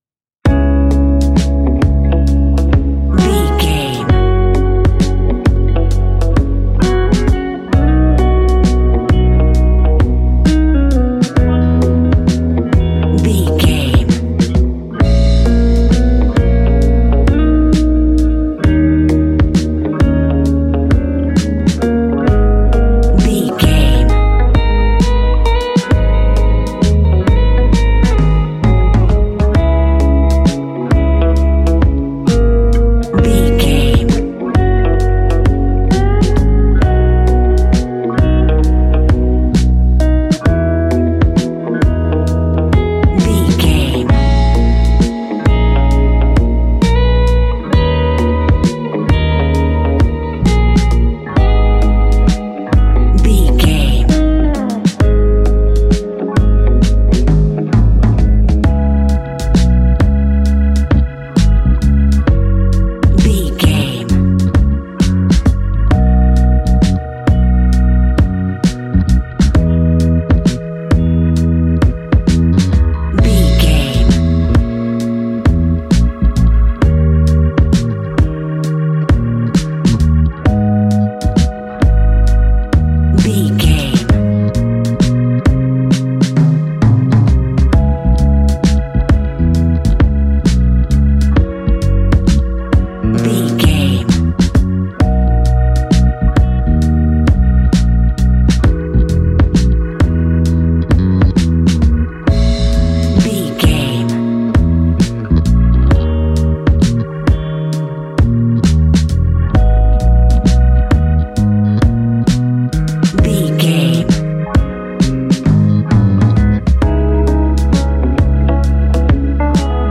Ionian/Major
G♭
chilled
laid back
Lounge
sparse
new age
chilled electronica
ambient
atmospheric
instrumentals